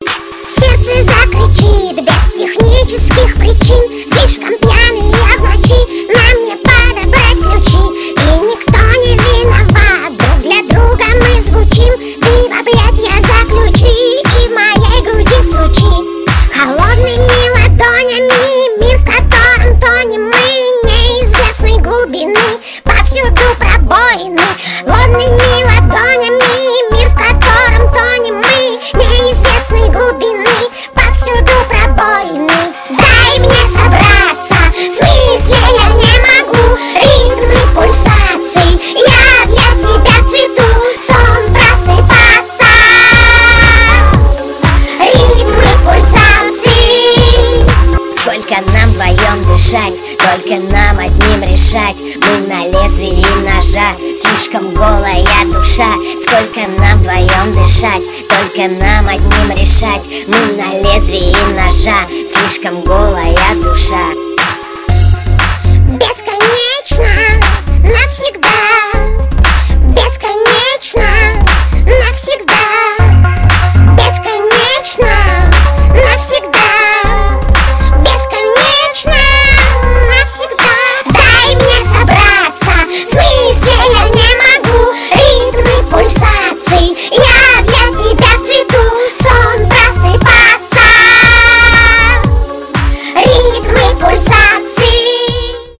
Cover
забавный голос